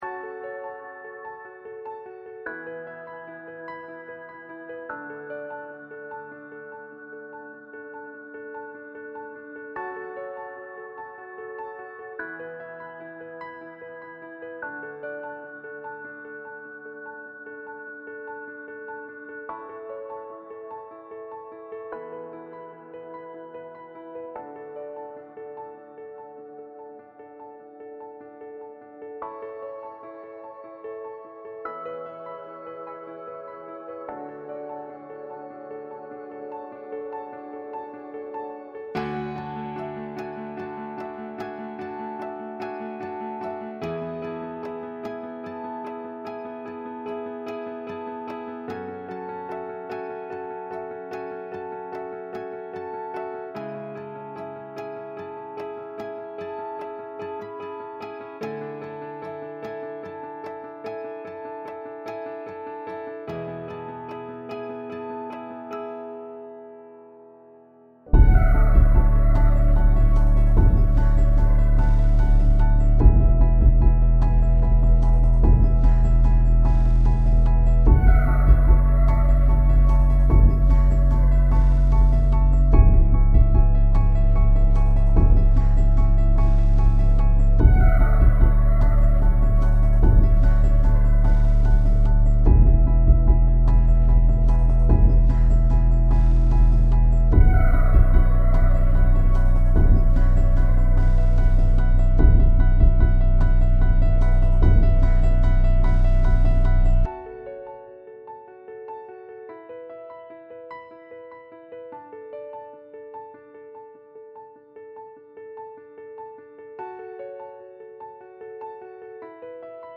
Largo [0-10] tristesse - piano - - -